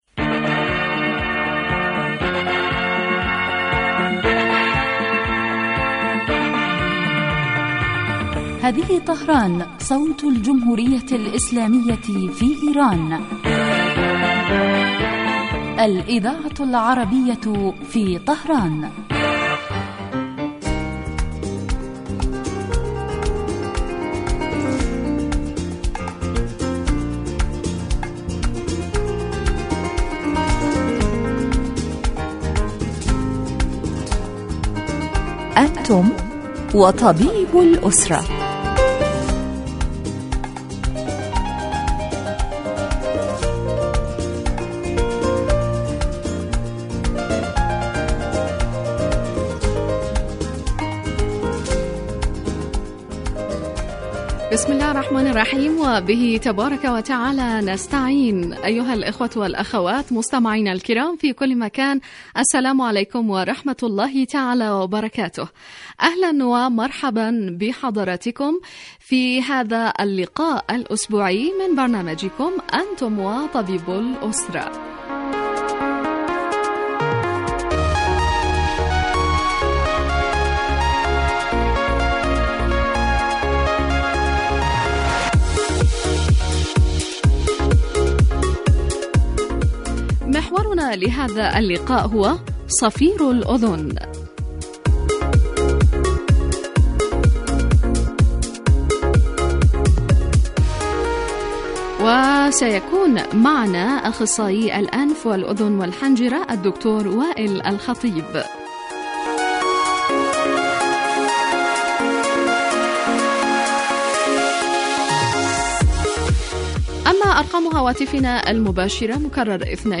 یتناول البرنامج بالدراسة والتحليل ما یتعلق بالأمراض وهو خاص بالأسرة ویقدم مباشرة من قبل الطبیب المختص الذي یرد کذلک علی أسئلة المستمعین واستفساراتهم الطبیة